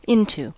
Ääntäminen
IPA: /aʊ̯f/